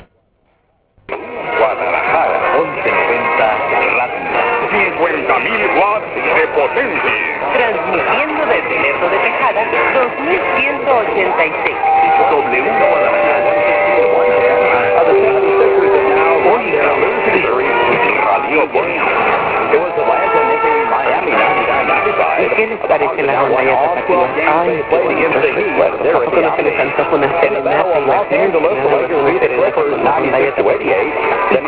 They run mostly talk programming (I received many cassettes with recordings of their programs) with a crew of about 100, 60 of which are freelancers. To get a taste of their style, listen to an excerpt of the special greetings sent to me on tape.